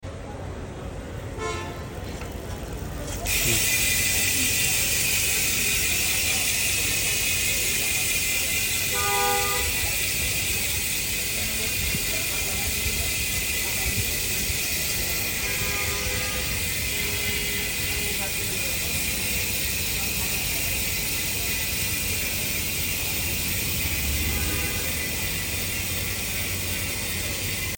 GUB 1351 hub sound & sound effects free download
GUB 1351 hub sound & ROLLING